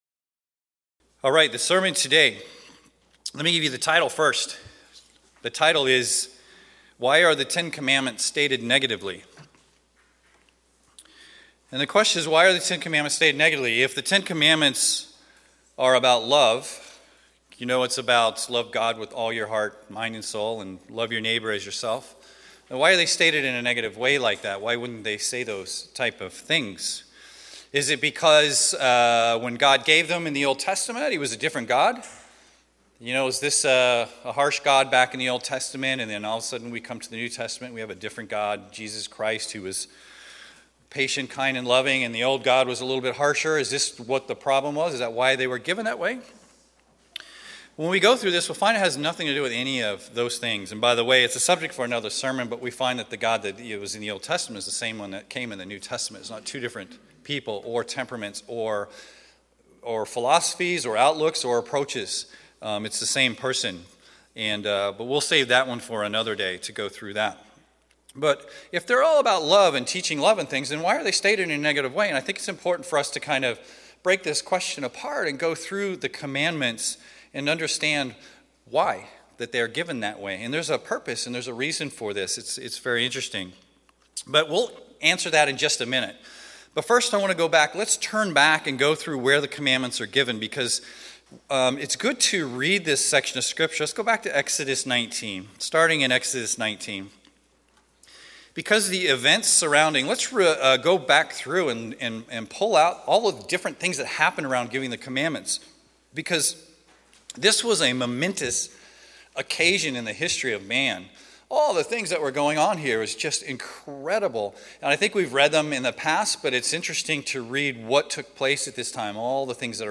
If the commandments are about love, why are they stated in a negative way (thou shall not...)? This sermons examines the 10 commandments in a new light and deeper way.
Given in Seattle, WA